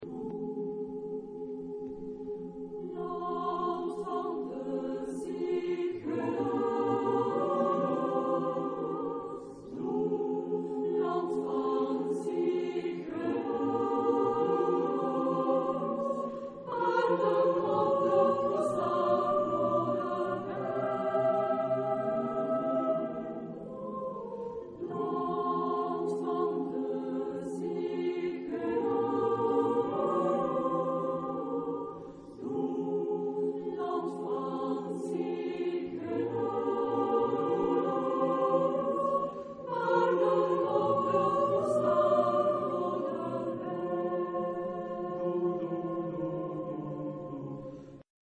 Genre-Style-Form: Popular ; Secular ; Contemporary
Mood of the piece: narrative ; affectionate ; expressive
Type of Choir: SATB  (4 mixed voices )
Tonality: F minor